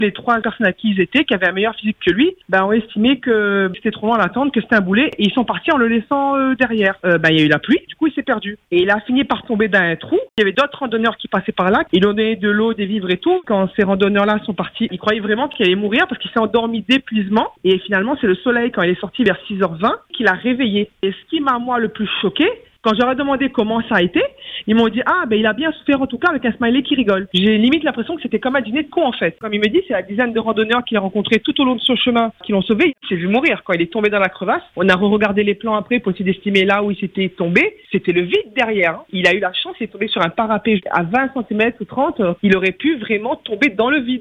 Commencent alors deux jours et une nuit d’errance et d’angoisse, une expérience éprouvante que sa mère décrit aujourd’hui avec émotion et colère.